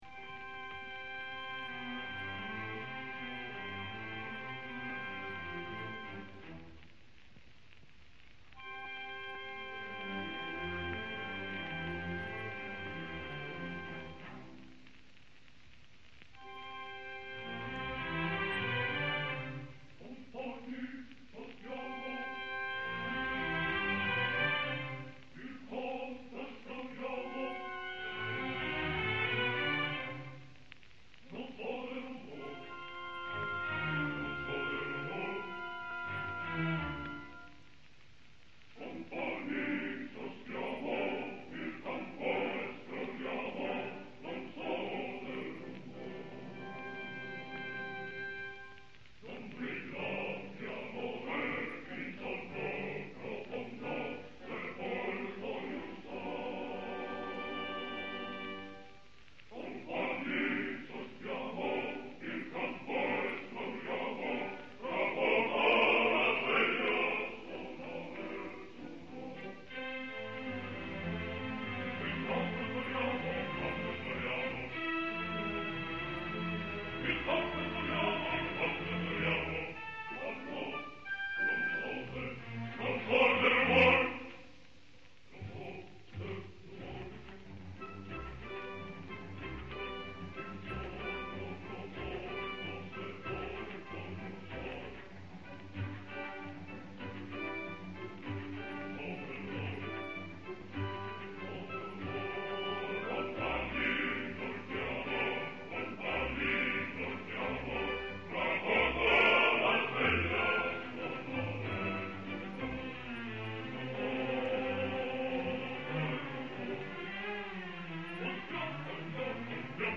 registrazione in studio.